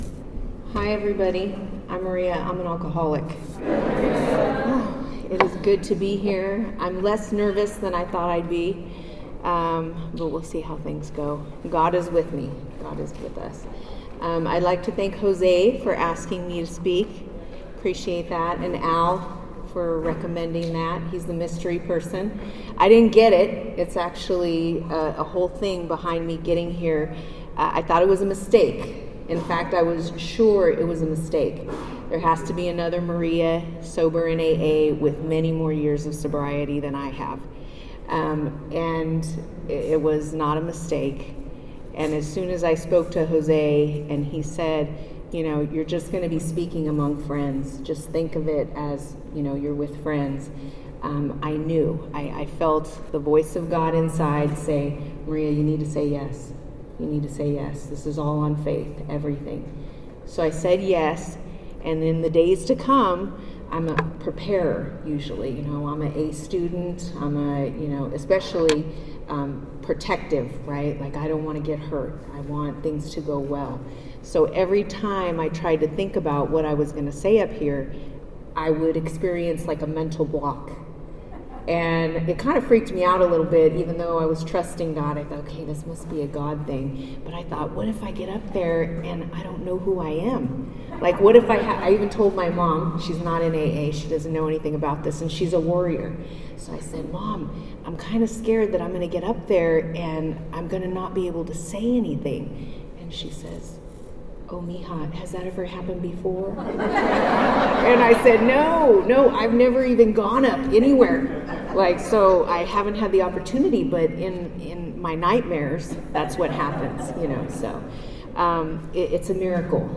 2022 All Welcome Speaker Meeting - Ice Cream Social